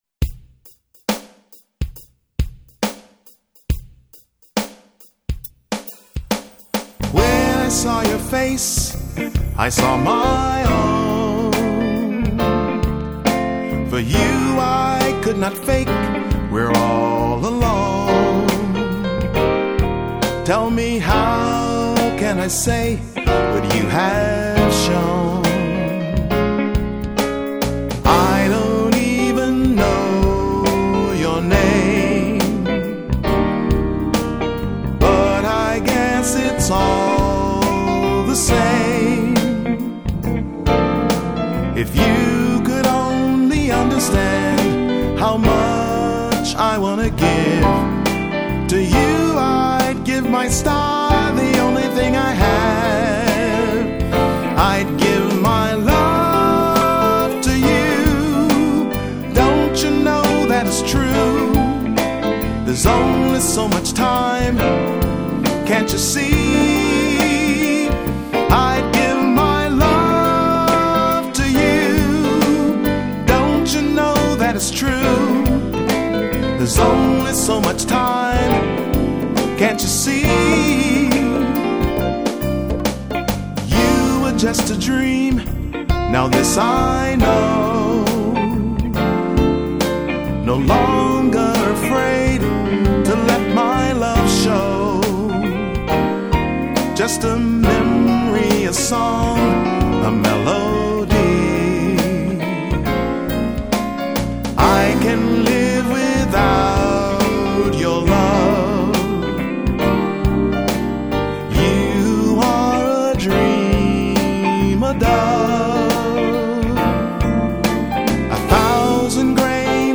vocals and keys
Guitar